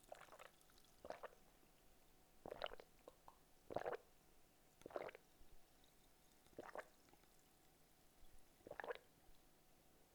Carbonated Beverage Drinking Sound Effect Free Download
Carbonated Beverage Drinking